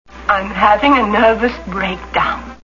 Charade Movie Sound Bites